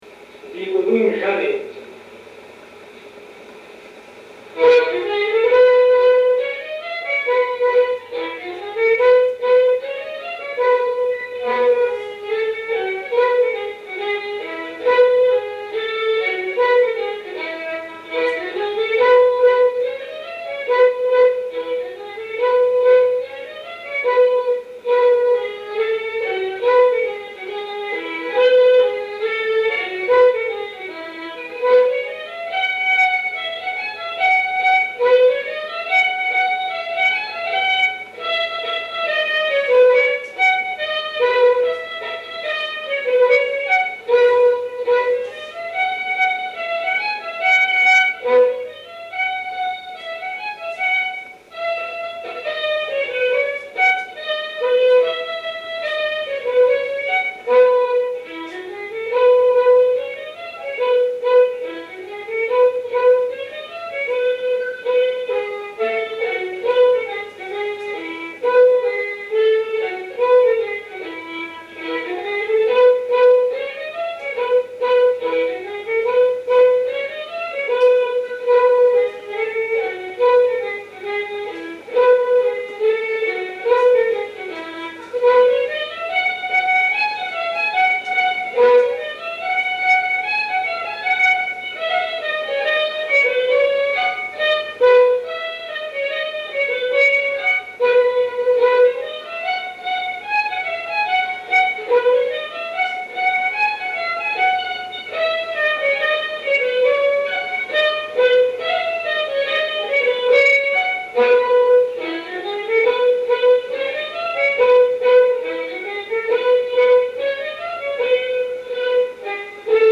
Scottish double (fredonné)